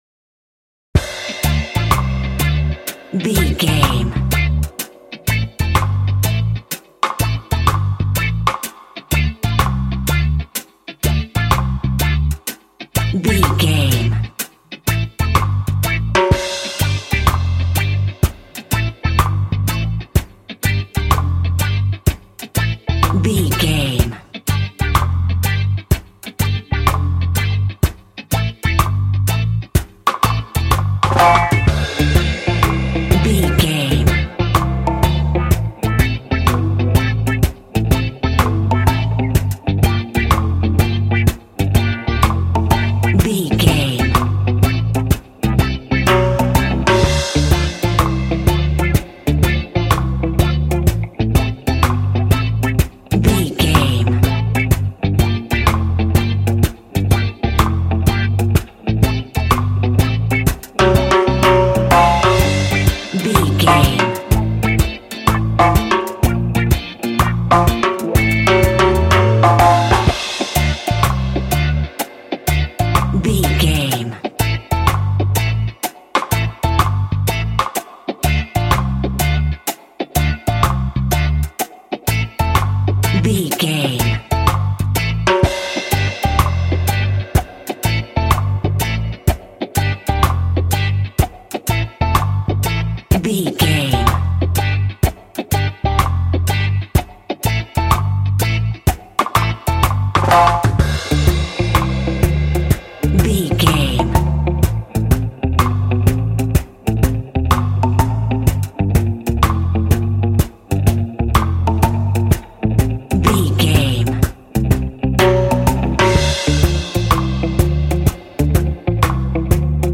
Classic reggae music with that skank bounce reggae feeling.
Aeolian/Minor
D
cheerful/happy
mellow
drums
electric guitar
percussion
horns
electric organ